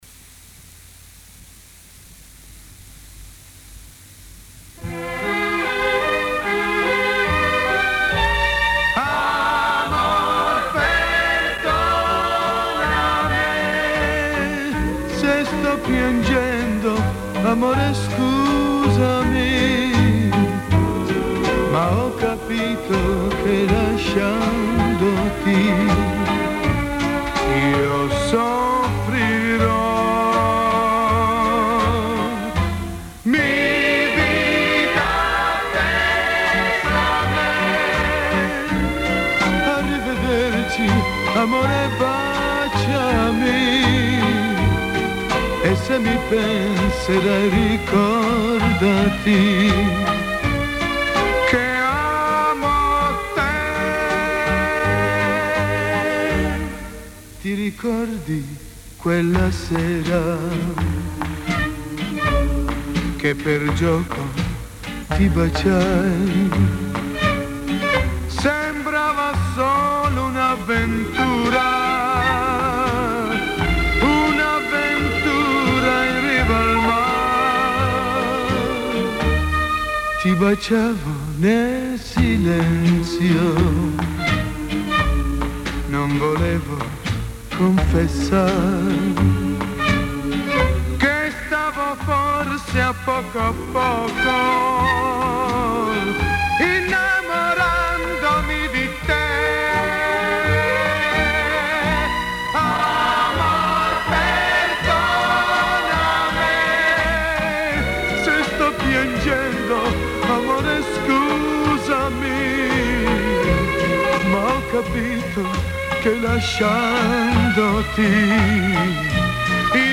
(без обработки)